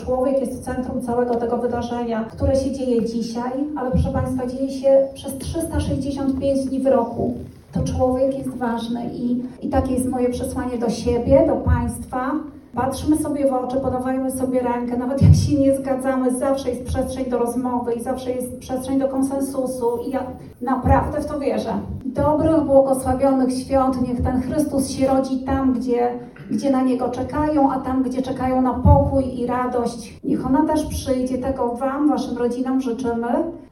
Występy młodzieży z Regionalnego Ośrodka Kultury, wspólne śpiewanie kolęd, rozmowy i moc życzeń – tak wyglądało spotkanie wigilijne, które odbyło się w piątek (20.12) w Starostwie Powiatowym w Łomży.
Życzenia do wszystkich zebranych gości skierowała wicestarosta, Anna Gawrych: